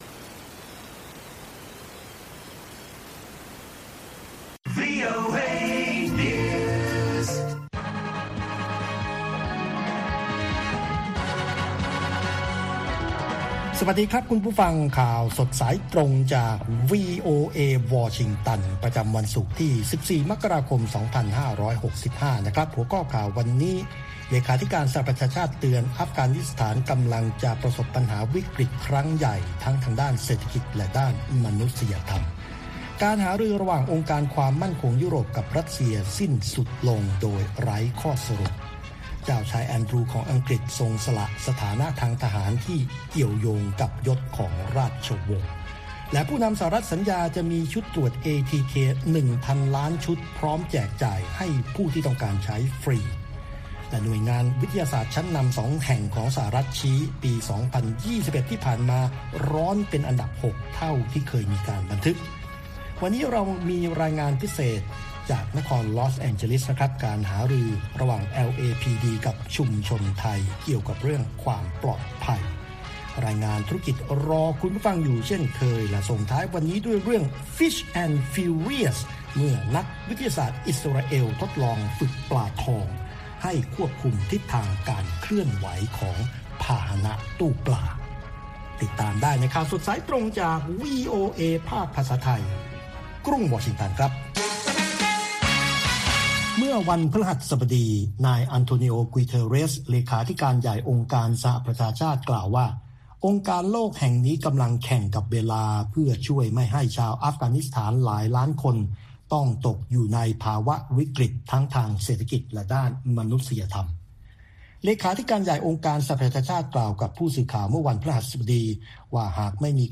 ข่าวสดสายตรงจากวีโอเอ ภาคภาษาไทย ประจำวันศุกร์ที่ 14 มกราคม 2565 ตามเวลาประเทศไทย